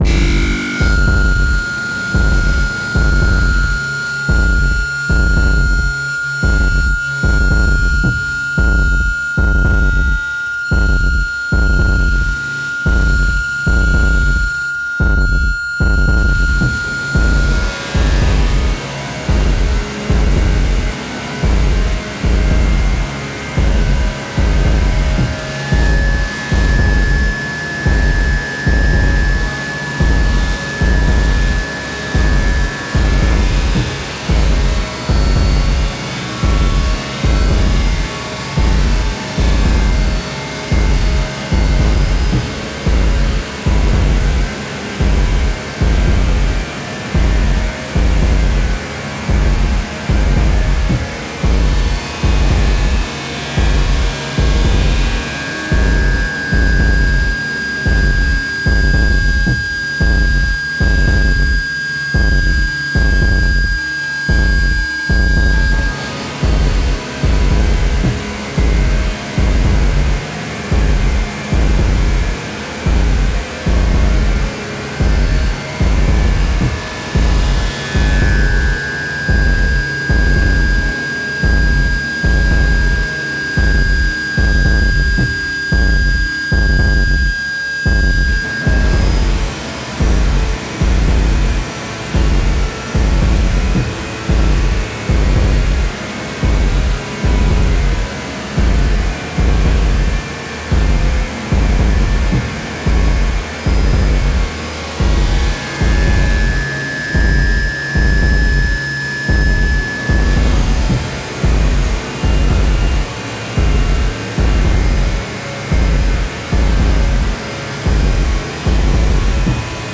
大地を轟かす太く重い狼の”鼓動”。
天と地を結ぶ霊獣の為の、美しく力強い祝祭曲。